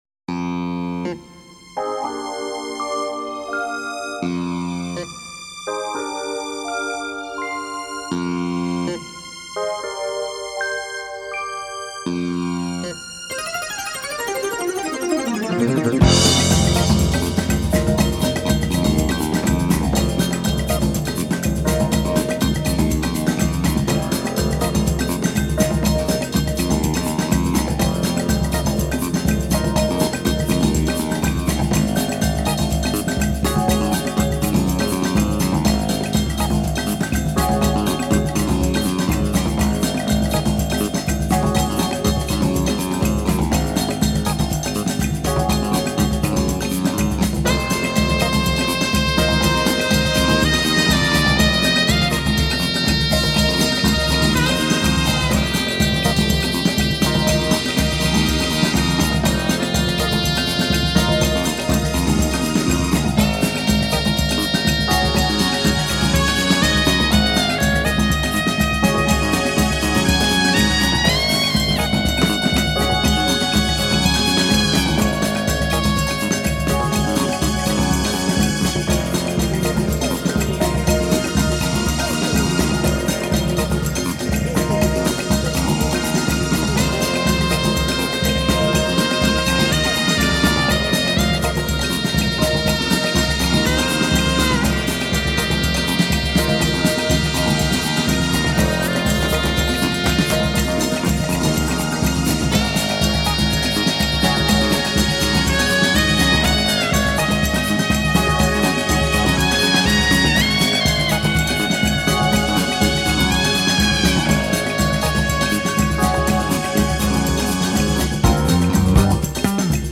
Есть и рок-композиции и джаз-рок. Есть диско-композиции.